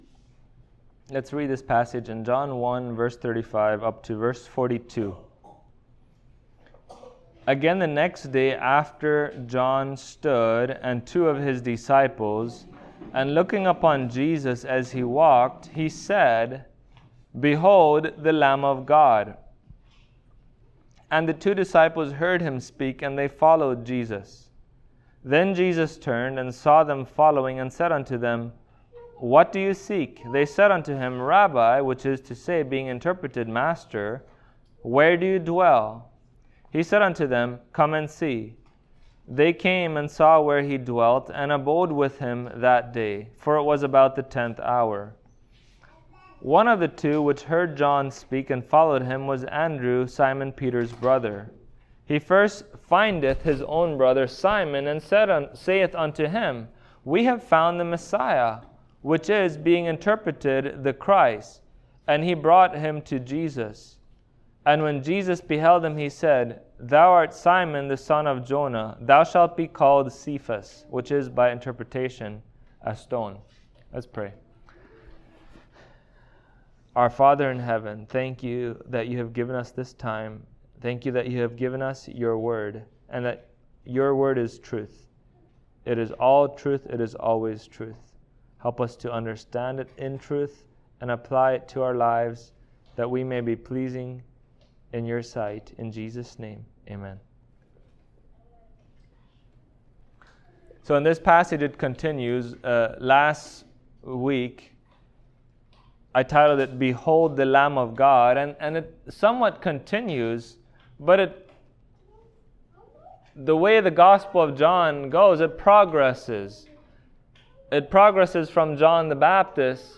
John 1:35-42 Service Type: Sunday Morning Topics: Jesus Choosing his Disciples « Behold the Lamb of God The Divine Call.